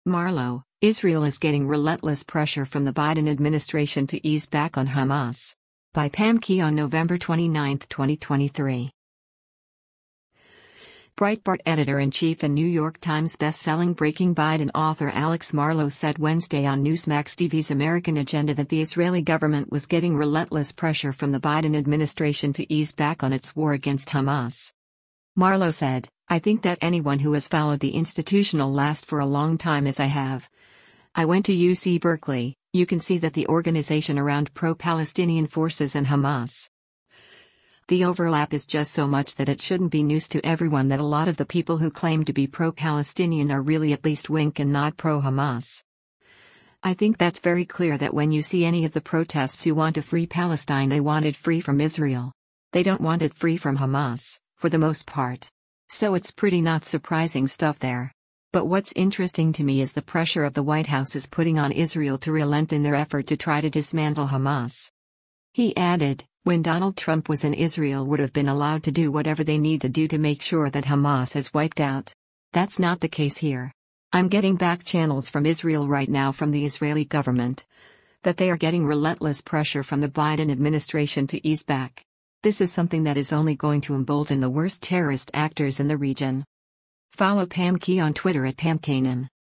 Breitbart Editor-in-Chief and New York Times bestselling Breaking Biden author Alex Marlow said Wednesday on Newsmax TV’s “American Agenda” that the Israeli government was “getting relentless pressure from the Biden administration to ease back” on its war against Hamas.